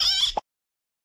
CARTOON - POP 04
Category: Sound FX   Right: Both Personal and Commercial